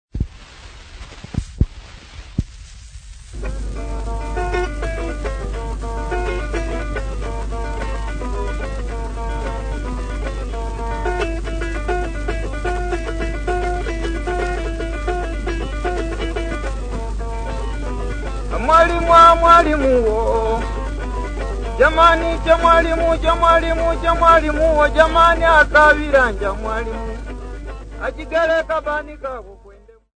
Popular music--Africa
Field recordings
Africa Malawi city not specified f-mw
sound recording-musical
Indigenous music